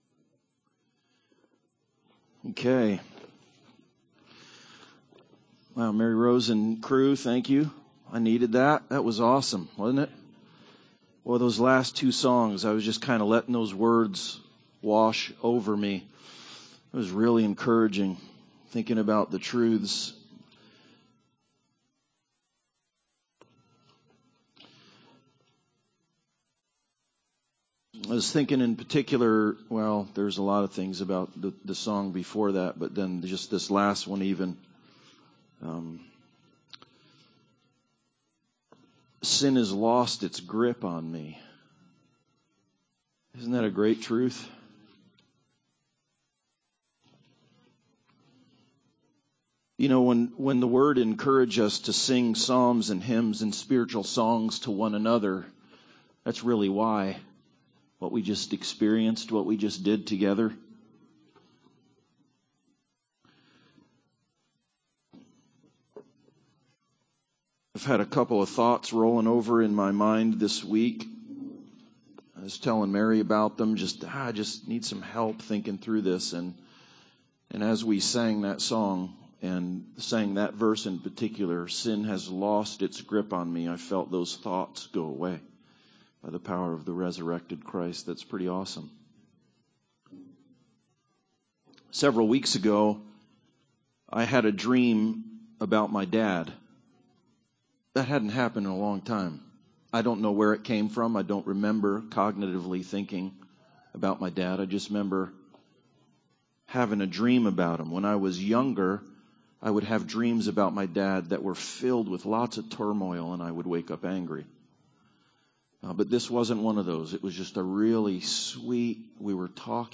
Luke 24:13-49 Service Type: Sunday Service Bible Text